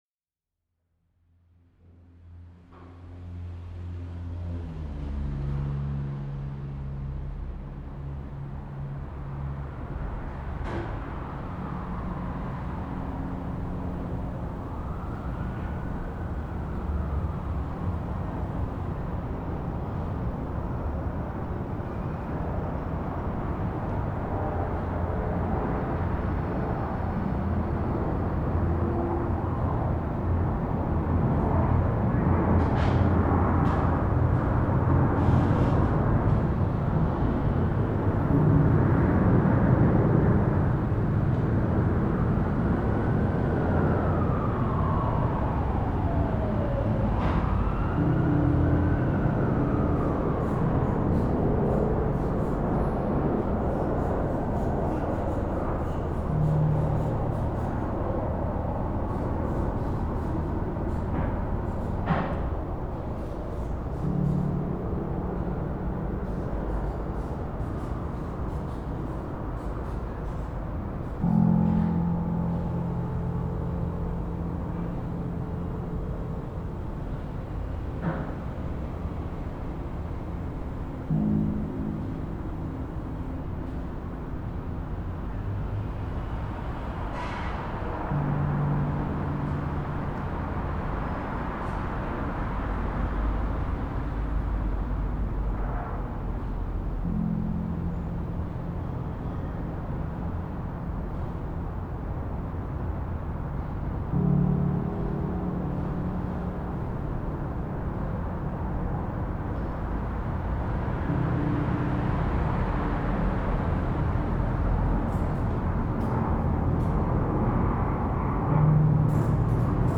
for two pianists, with one piano, for a long time